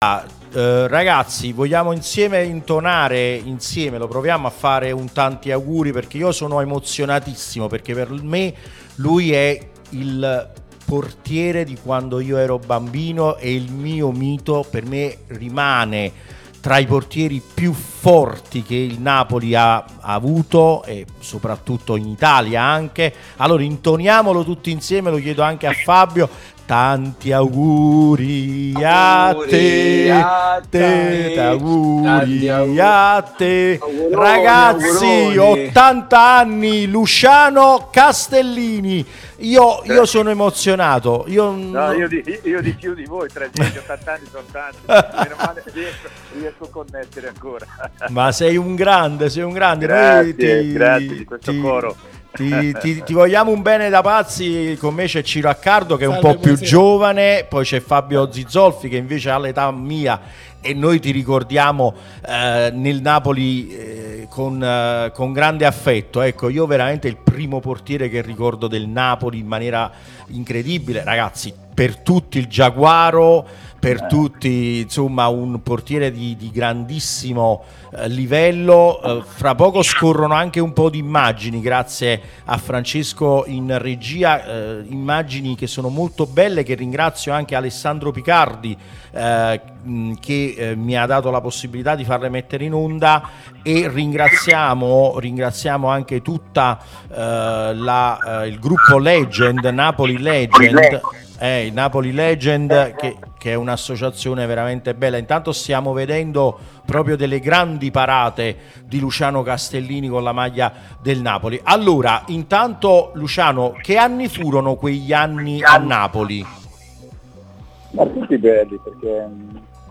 Luciano Castellini festeggia i suoi 80 anni in diretta con noi.